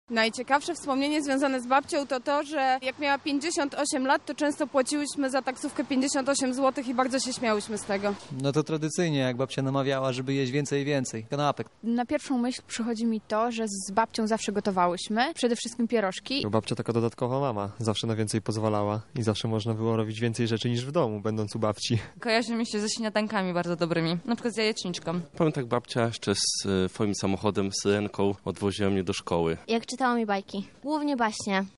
Nasz reporter zapytał mieszkańców Lublina o wspomnienia z dzieciństwa związane z babciami.
sonda dzień babci